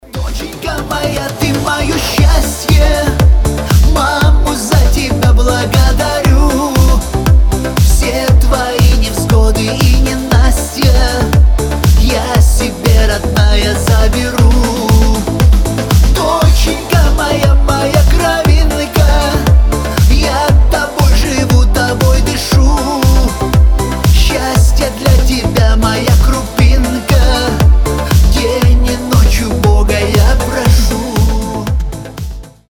Рингтоны шансон
Душевные